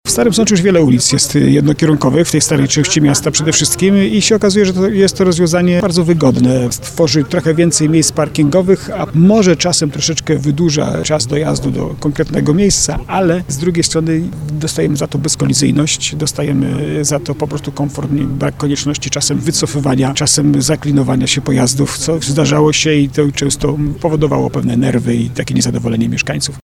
– Choć ulica jest wąska, jeździ tędy dużo kierowców, bo to najkrótsze połączenie rynku z mostem św. Kingi – mówi burmistrz Jacek Lelek.